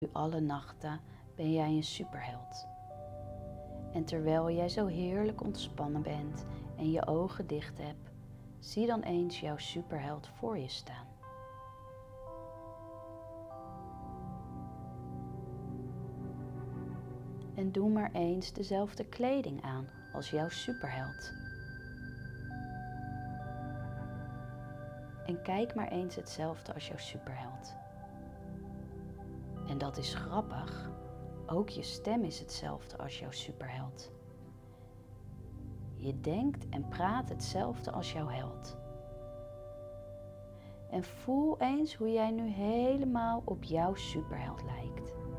• Begeleidt je kind met een zachte en kalmerende stem naar een diepe slaap.
Meditatie-veilig-in-de-nacht-Preview.mp3